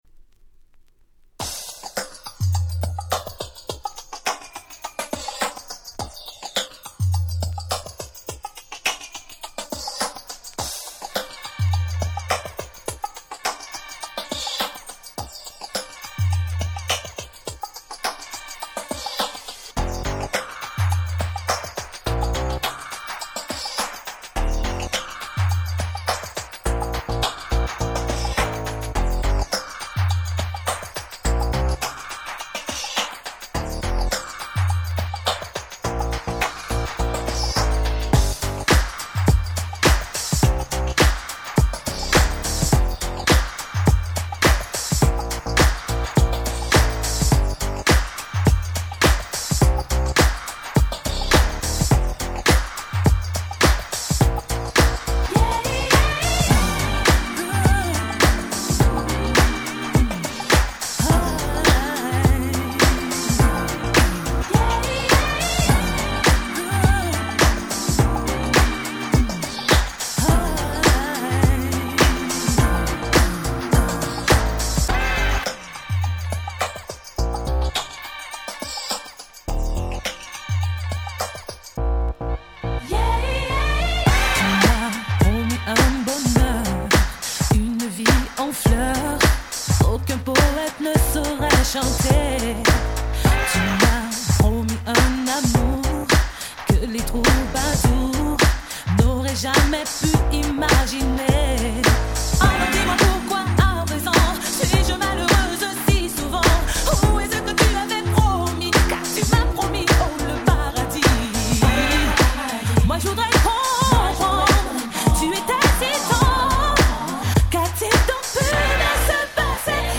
97' Nice Cover French R&B !!
Beatもオリジナルよりフロア向けで非常に使えます！！